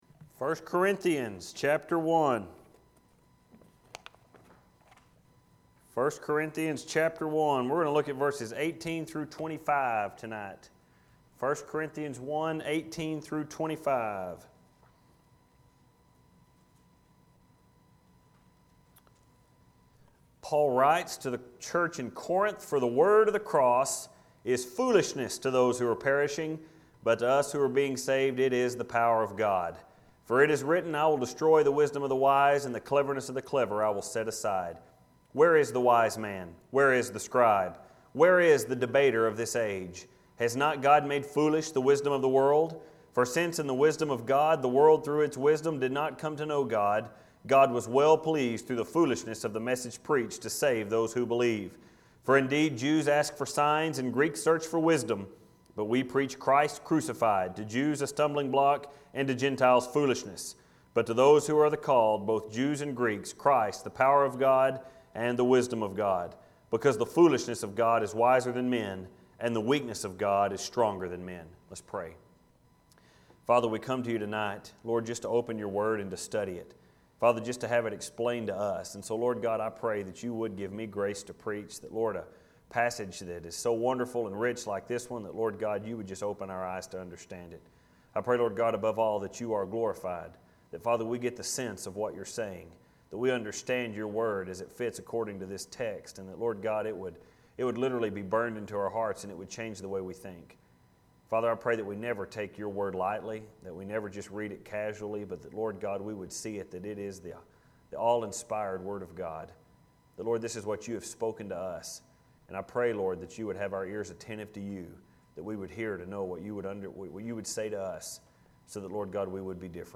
Filed Under: Sermons Tagged With: Corinthians